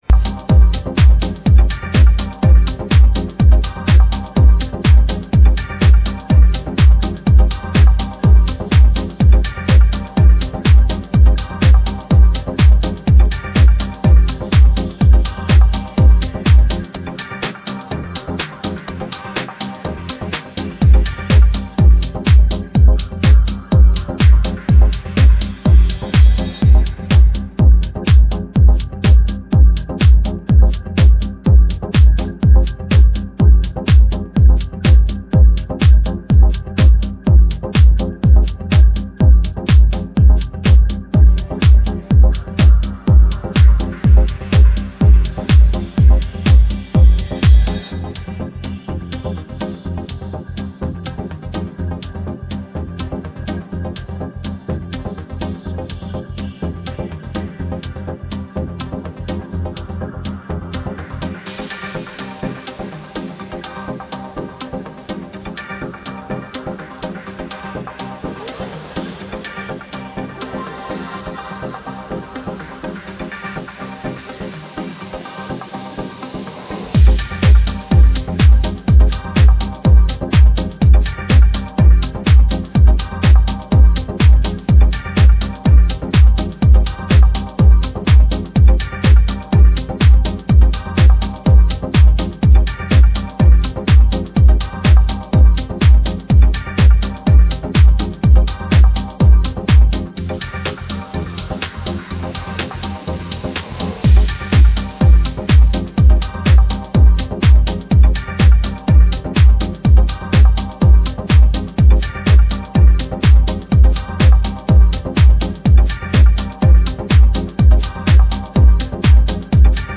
deep, moody synthesis and precise, punchy drums